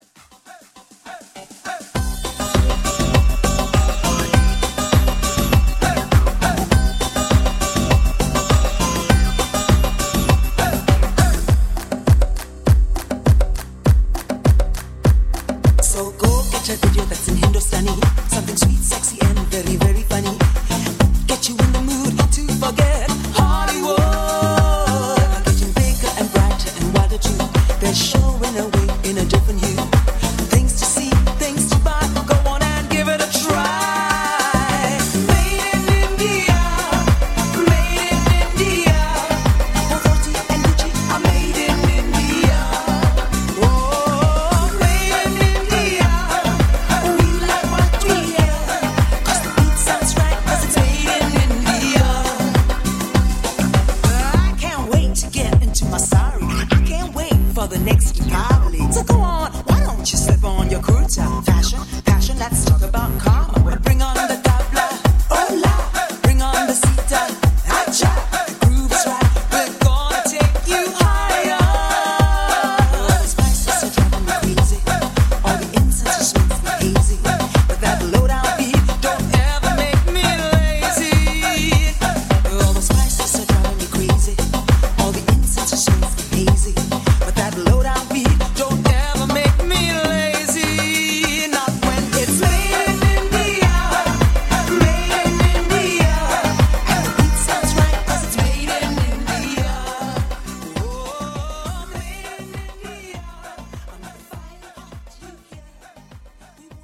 音质、音场、音效清晰震撼，新潮流行舞曲音乐元素，
加上欧美最迷幻时尚的舞曲，编配超强动感和节奏，
2000多首lounge、house、minimal、
劲爆的士高火爆大碟！神秘、空旷、悠然、激情。
全部改编自当今最热流行曲，音乐舞拍混然一体，